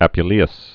(ăpyə-lēəs), Lucius fl. second century AD.